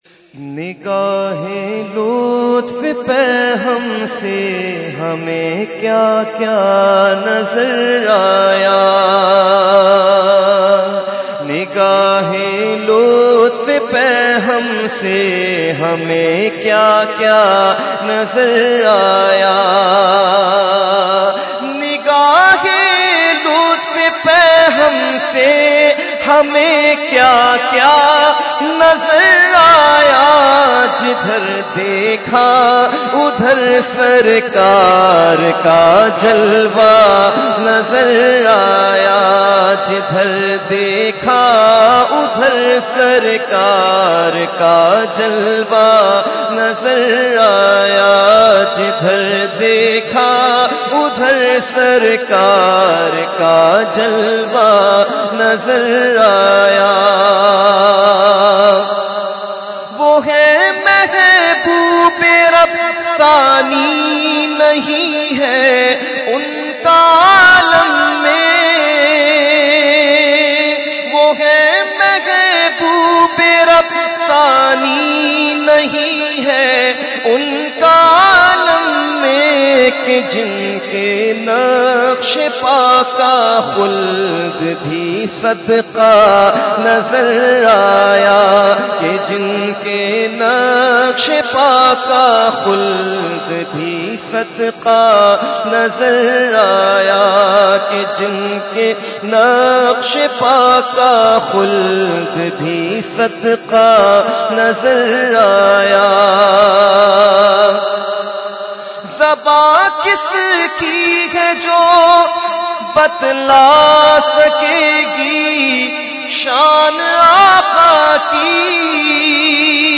Category : Naat | Language : UrduEvent : Urs Qutbe Rabbani 2014